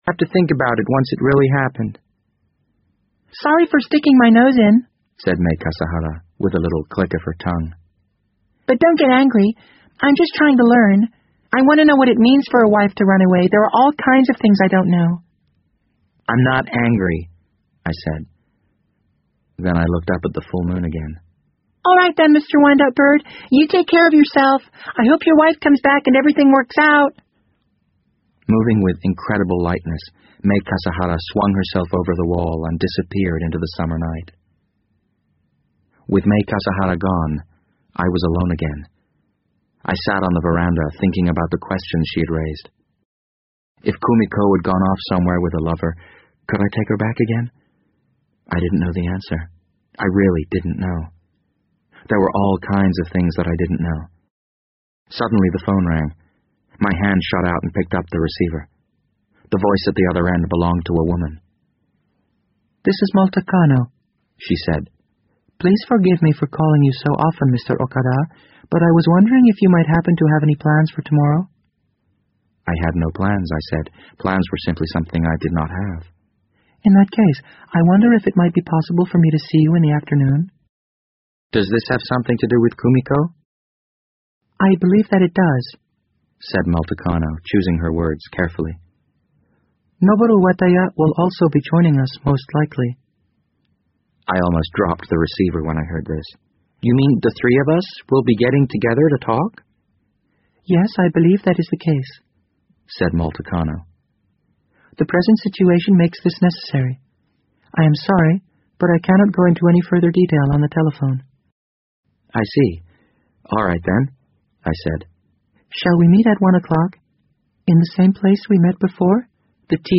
BBC英文广播剧在线听 The Wind Up Bird 005 - 17 听力文件下载—在线英语听力室